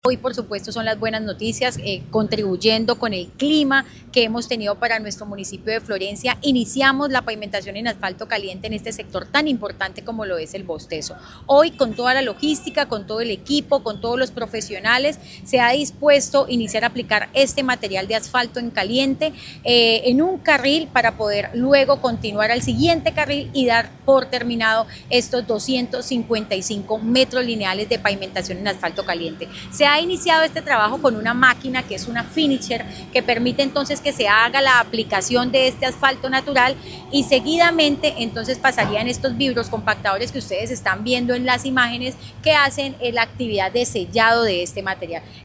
Karla Liscano, secretaria de Obras Públicas de Florencia, indicó que, se espera que hoy viernes 4 de abril se finalice la intervención de la otra mitad de la calzada correspondiente a dicho carril.